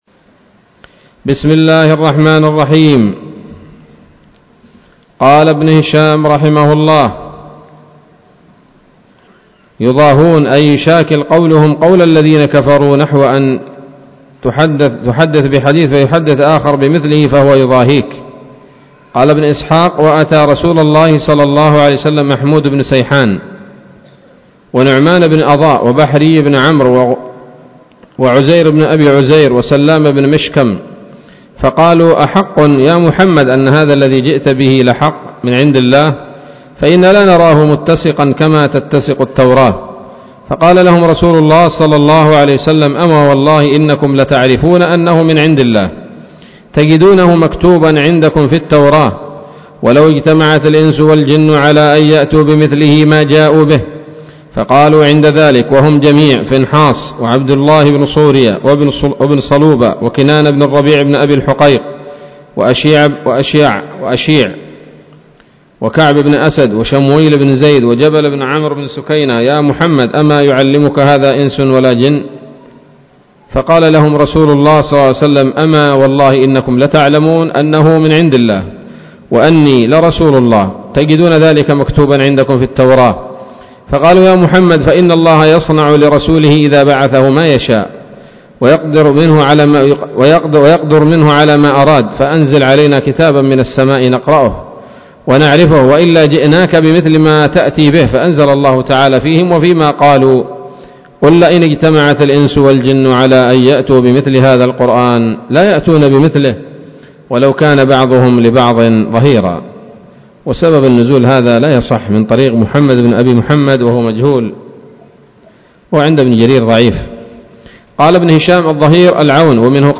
الدرس الثامن والتسعون من التعليق على كتاب السيرة النبوية لابن هشام